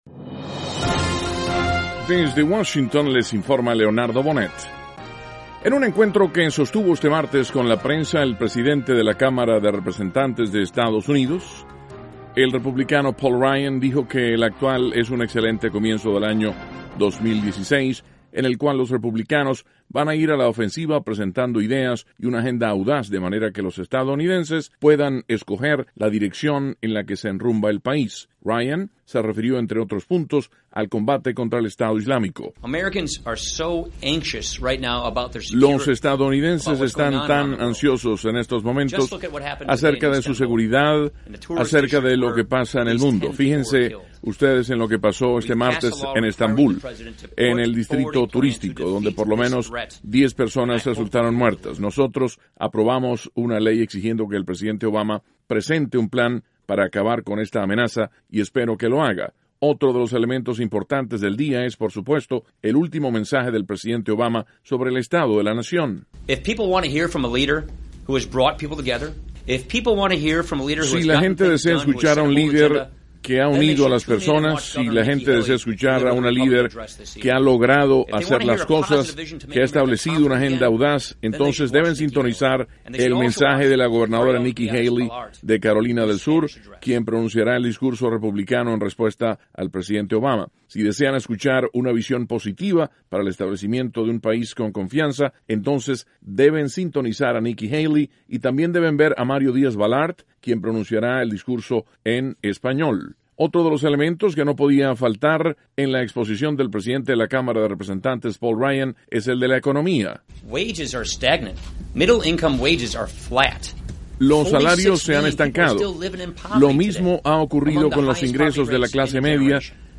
El presidente de la Cámara de Representantes de Estados Unidos, Paul Ryan, conversa con la prensa sobre el discurso del presidente Obama sobre el Estado de la Nación, sobre el combate contra el Estado Islámico y sobre la economía nacional.